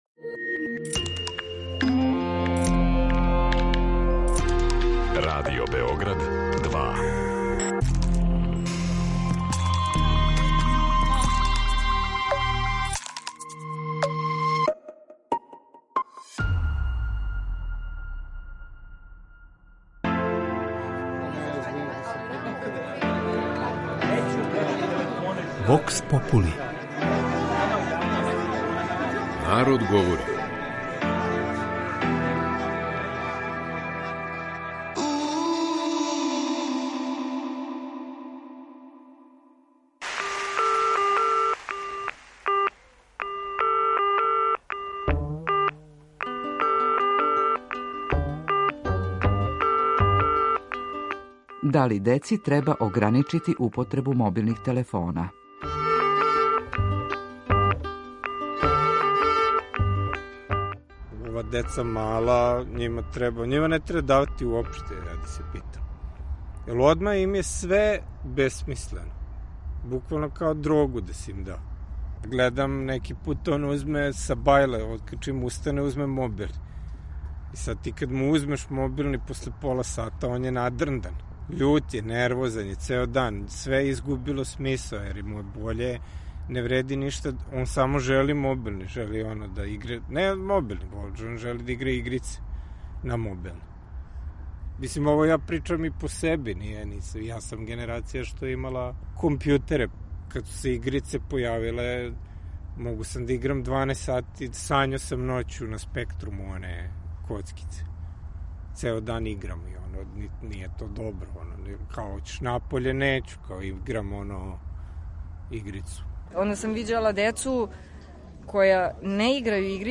VOX POP PON.mp3